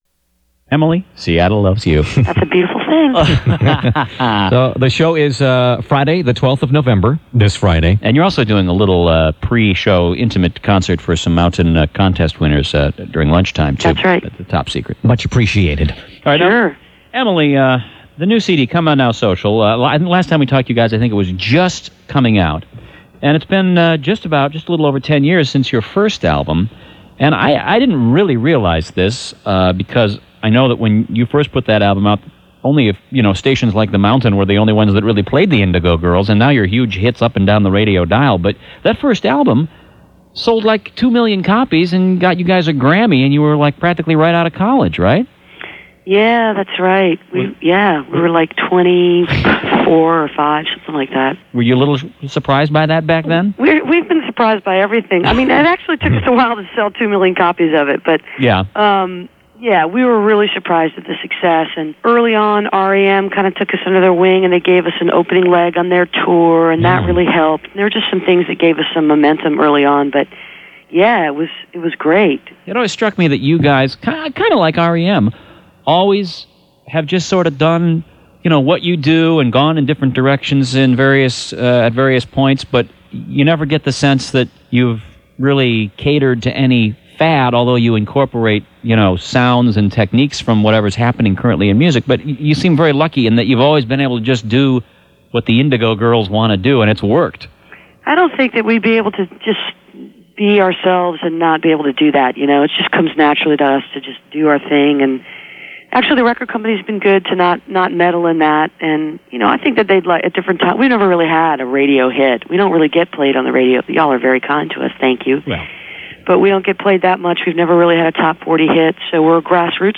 03. interview with emily (live on kmtt 11/9/99) (6:31)
tracks 1-4 live on kmtt - seattle, washington